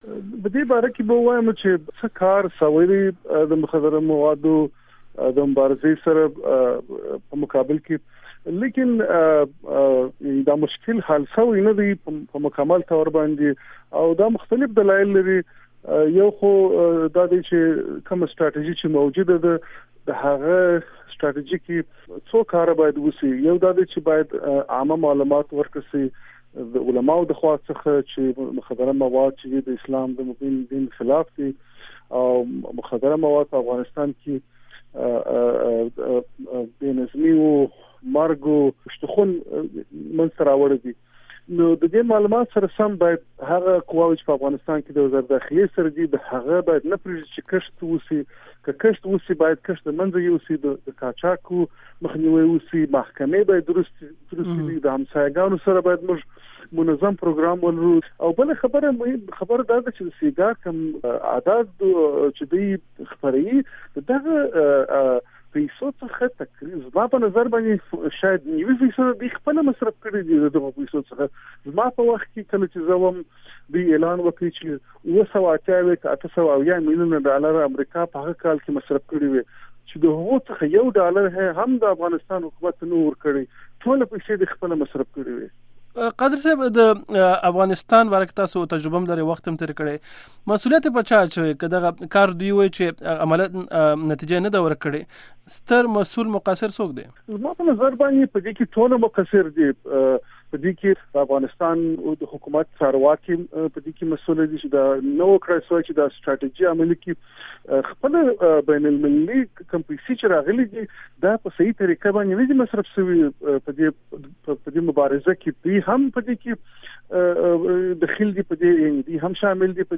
مرکې
د امریکا غږ آشنا راډیو په دې اړه د مخدره مواد ضد مبارزې د افغانستان پخواني وزیر ښاغلي حبیب قادري نه پوښتنه وکړه چې د مخدره موادو ضد مبارزې کې د ناکامۍ مسولیت د چا په غاړه دی.